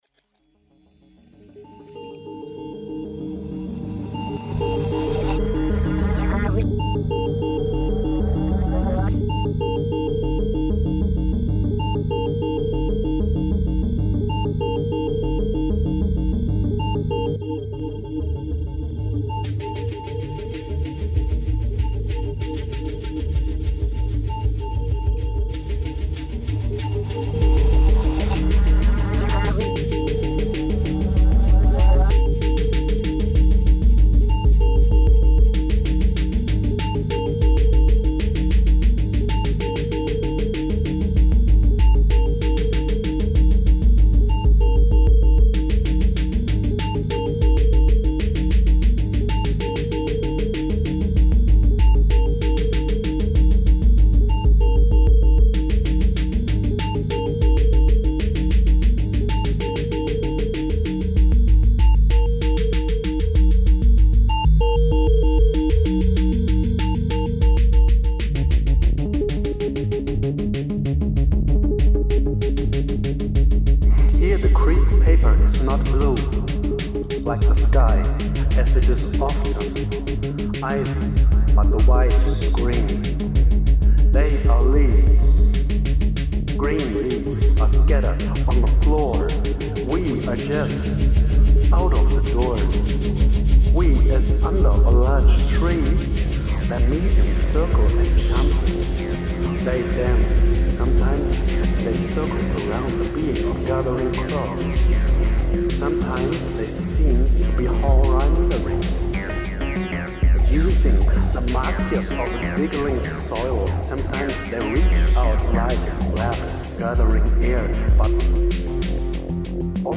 It is improvised music, with predetermined moods, and scales, exploratory music that seeks transmission and mediation.